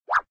SButtonSelect.ogg